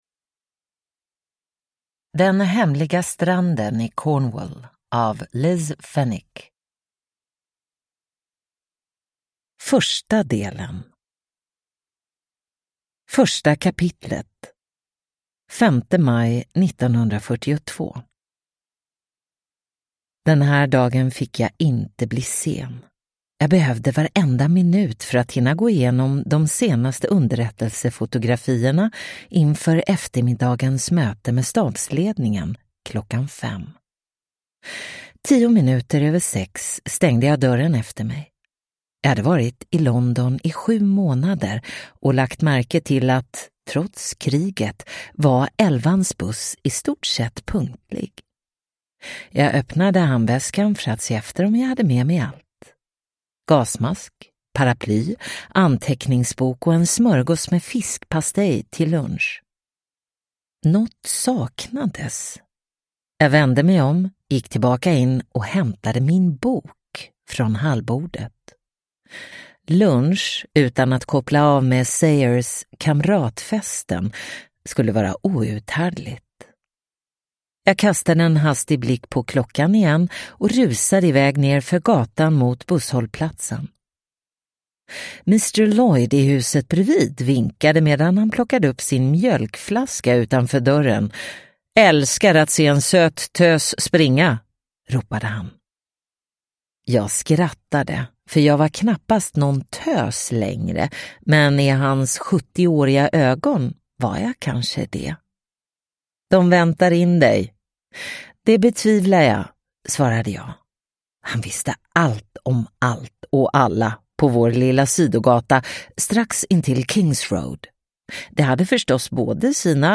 Den hemliga stranden i Cornwall – Ljudbok – Laddas ner